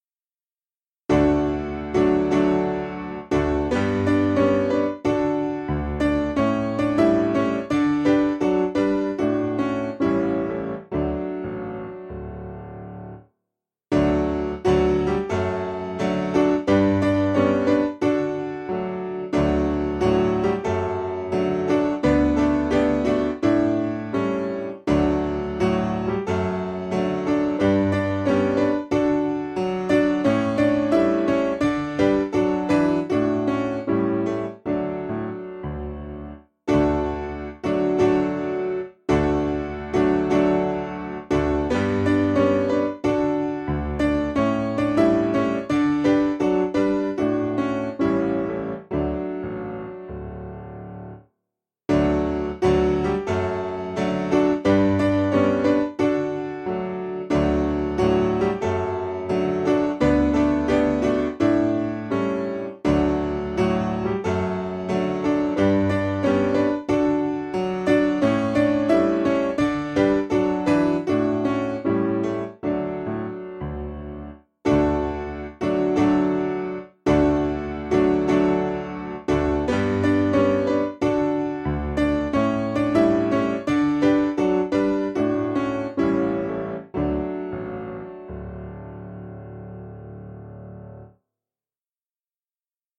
Key: D Major